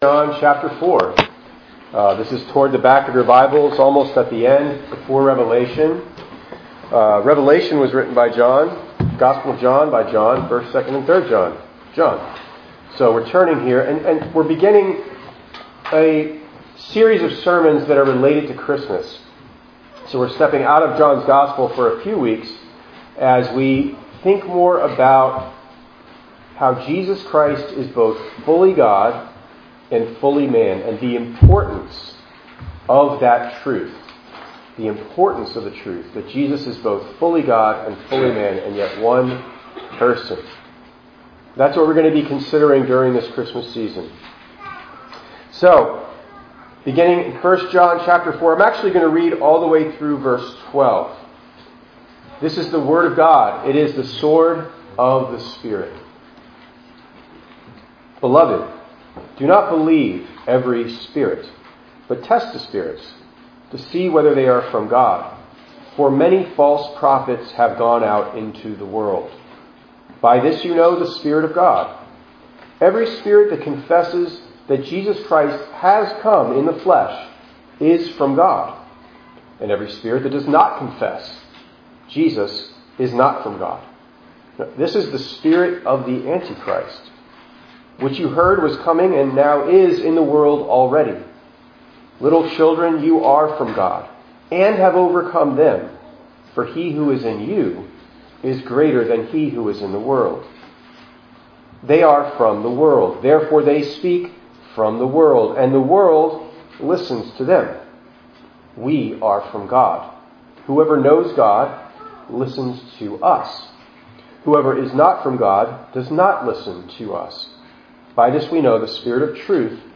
12_3_23_ENG_Sermon.mp3